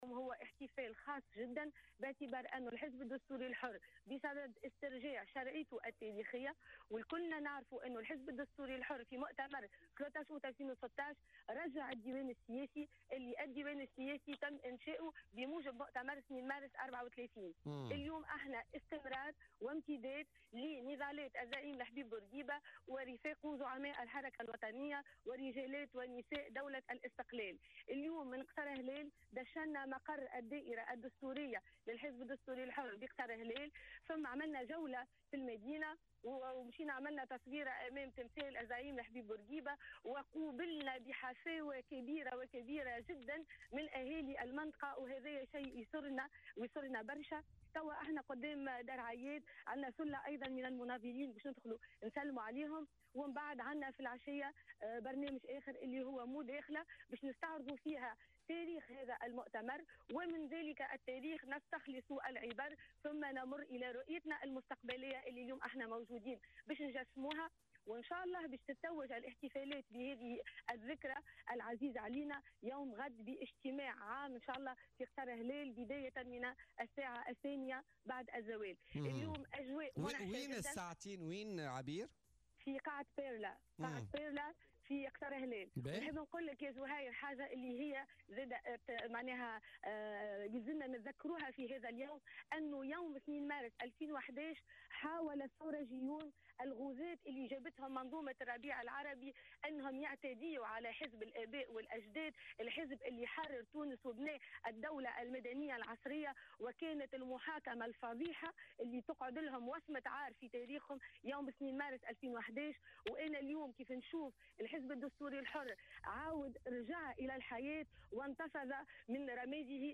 قالت عبير موسي رئيسة الحزب الدستوري الحر في مداخلة لها في بولتيكا اليوم الجمعة 2 مارس على هامش مشاركتها في الاحتفالات بالذكرى 84 لنشأة الحزب الحر الدستوري بمدرسة 2 مارس 1934 بقصر هلال إن حزبها بصدد استرجاع شرعيته التاريخية.